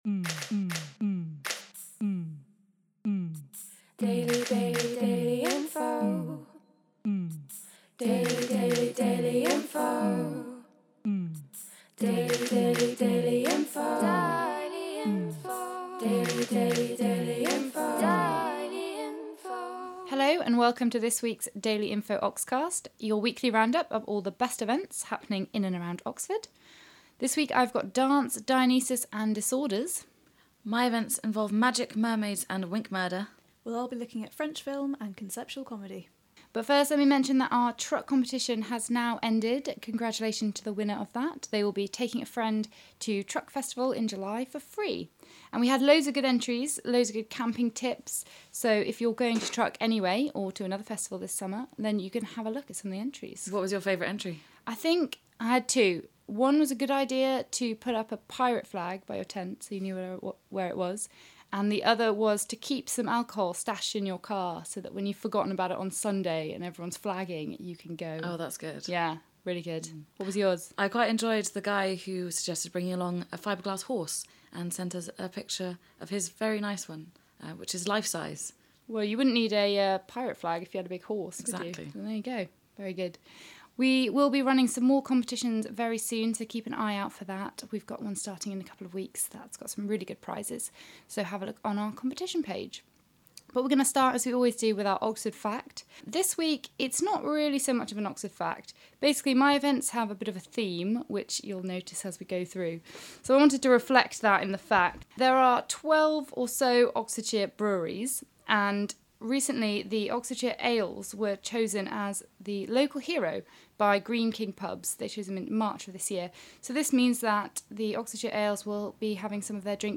In this week's Oxcast of Drinks, Dance and Disorder (in that order), the team discuss Greek Tragedy, Discworld and sculpture. Also listen to an interview with Cult Screens organiser